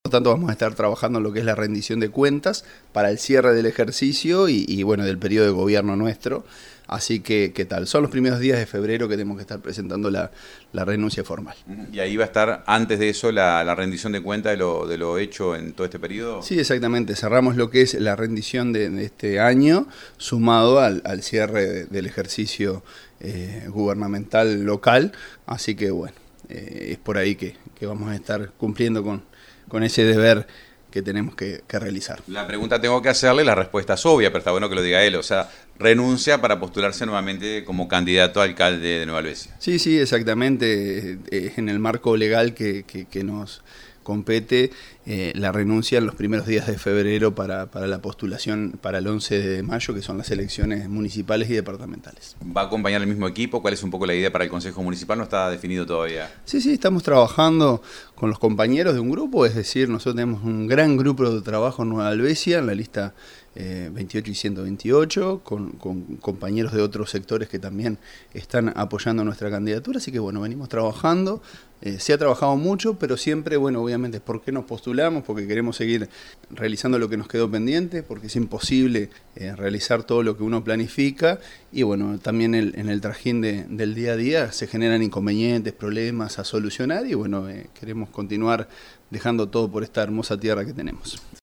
Escuchamos al alcalde Marcelo Alonso…